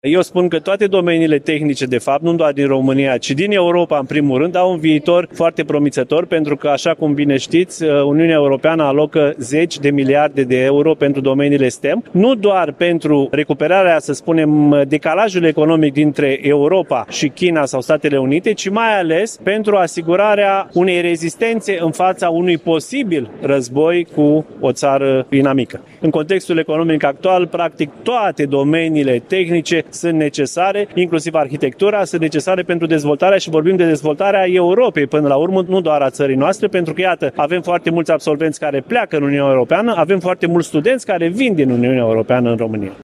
Ceremonia a avut loc în cadrul Zilelor Universității Tehnice „Gheorghe Asachi” din Iași, manifestări ce marchează împlinirea a 212 ani de la primul curs, în limba română, ținut de Gheorghe Asachi, și 88 de ani de la înființarea Politehnicii ieșene.